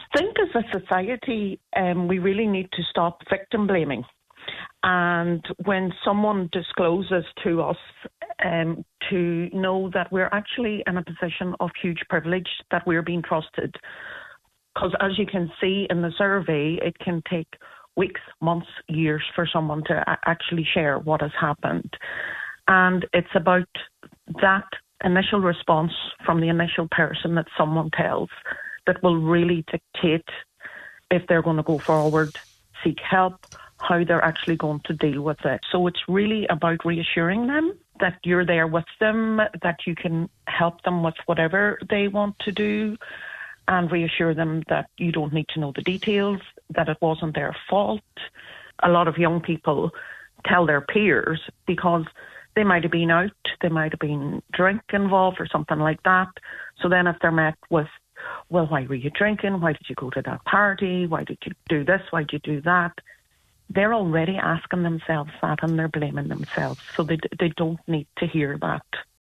on this morning’s Nine ’til Noon show